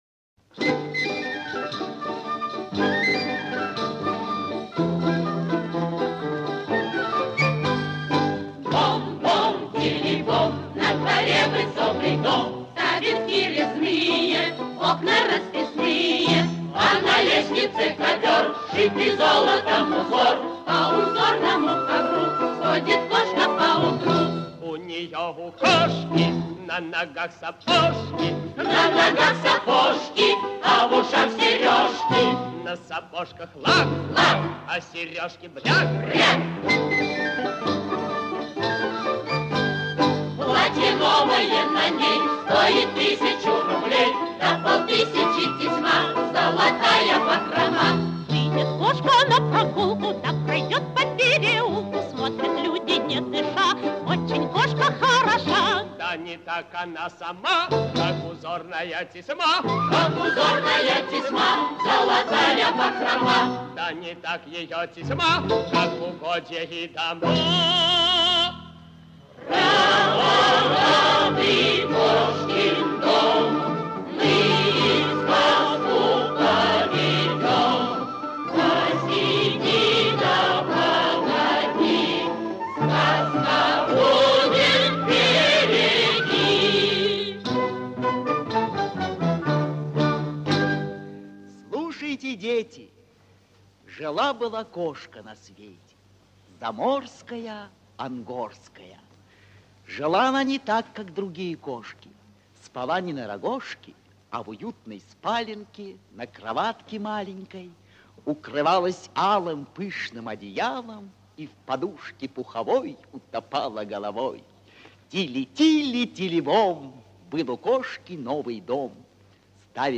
Слушать Кошкин дом – Маршак, без рекламы, без раздражителей, постановка и запись 1955 года.